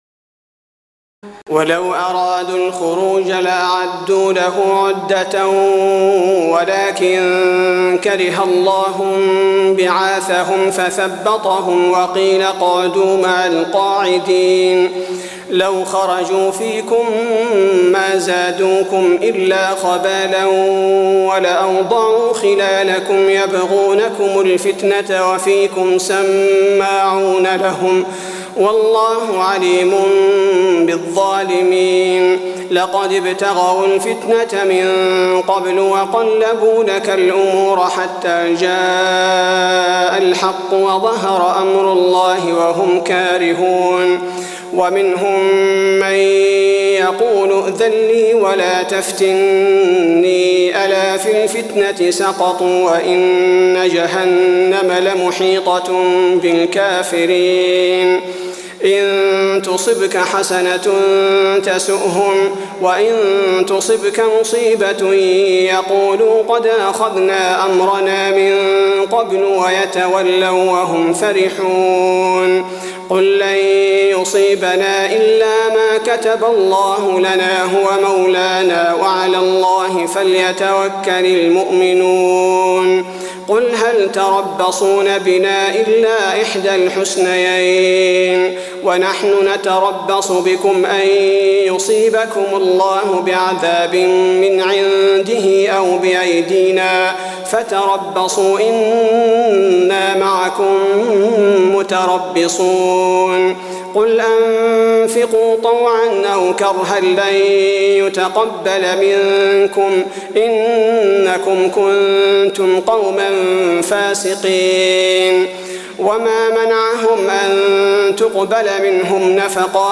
تراويح الليلة العاشرة رمضان 1423هـ من سورة التوبة (46-99) Taraweeh 10 st night Ramadan 1423H from Surah At-Tawba > تراويح الحرم النبوي عام 1423 🕌 > التراويح - تلاوات الحرمين